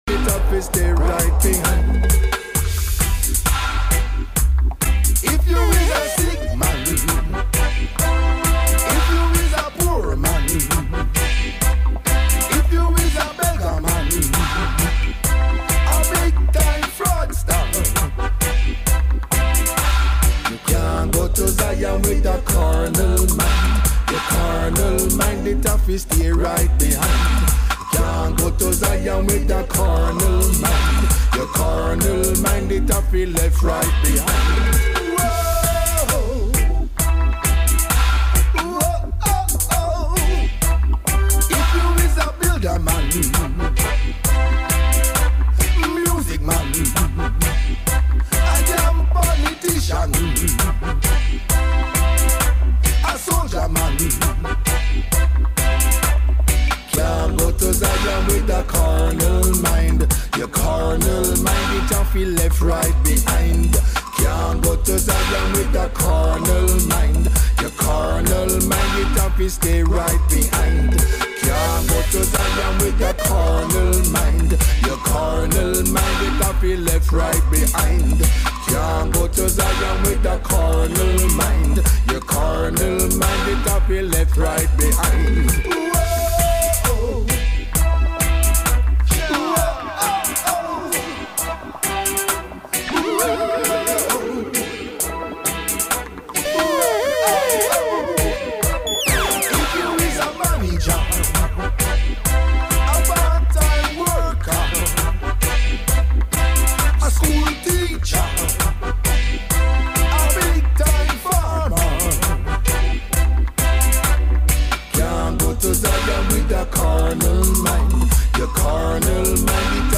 Reggae Roots & Dub Livications